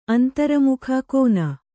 ♪ antaramukha kōna